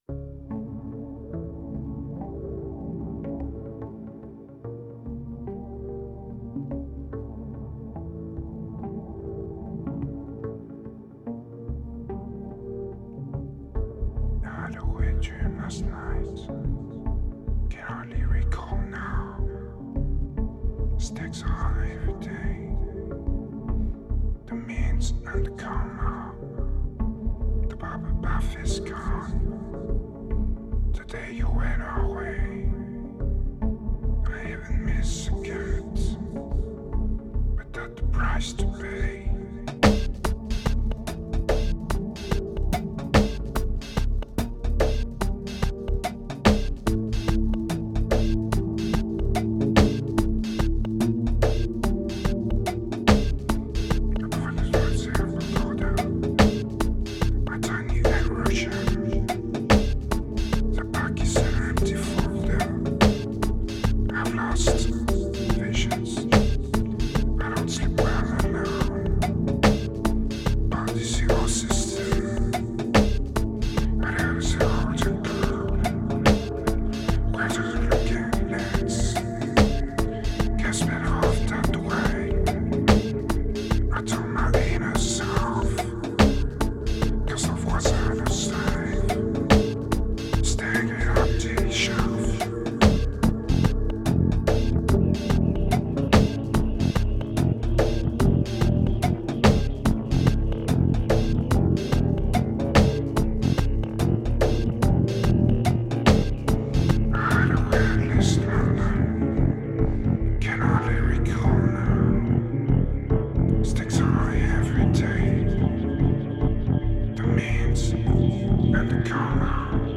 Pure remote desire, strong medication and raw electronica.
2434📈 - -40%🤔 - 145BPM🔊 - 2011-01-15📅 - -510🌟